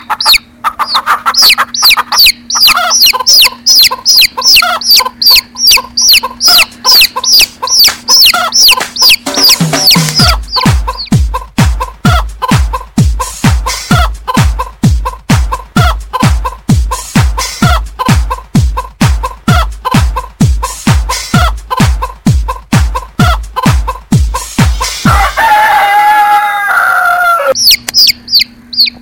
جلوه های صوتی
دانلود صدای حیوانات 33 از ساعد نیوز با لینک مستقیم و کیفیت بالا
برچسب: دانلود آهنگ های افکت صوتی انسان و موجودات زنده دانلود آلبوم مجموعه صدای حیوانات مختلف با سبکی خنده دار از افکت صوتی انسان و موجودات زنده